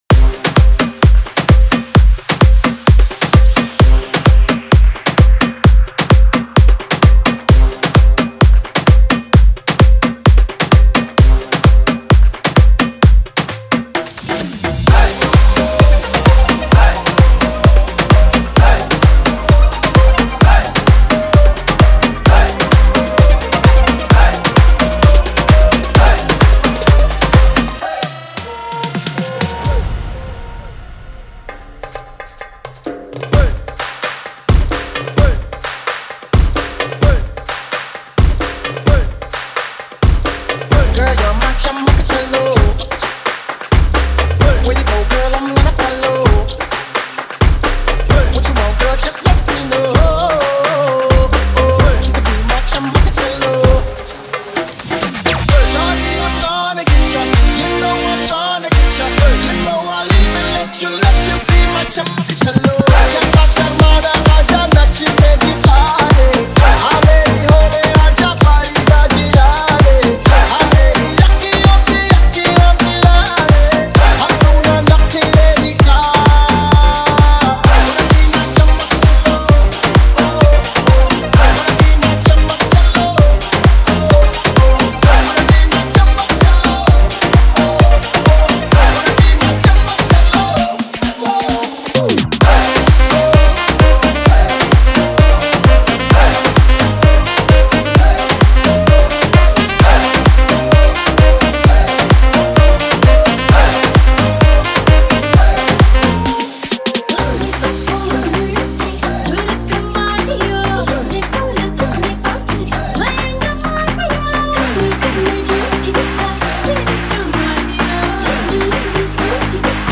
• Genre: Bollywood EDM / Club Mix
• BPM: 124–128 (Club Friendly)
• Punchy basslines and kicks
• Electronic synth layers
• Smooth build-ups and drops
• DJ-friendly intro and outro